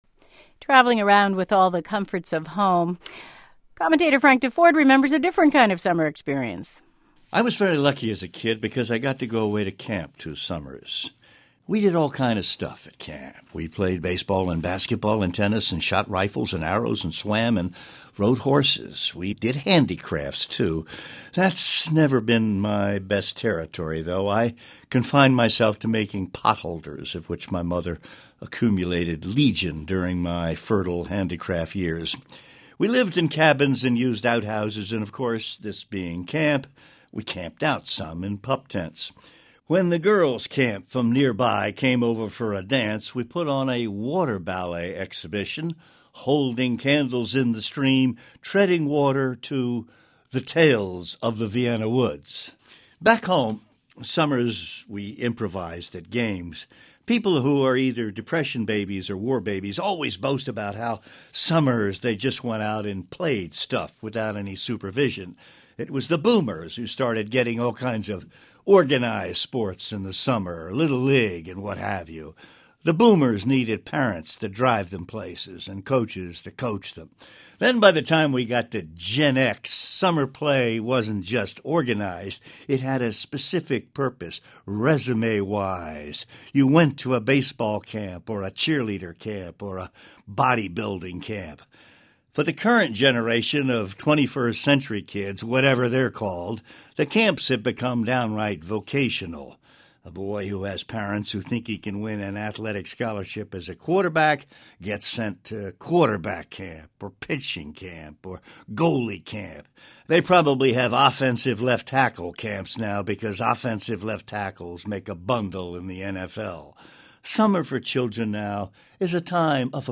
NPR Morning Edition's Frank Deford gives weekly commentary on a cross section of the world of sports.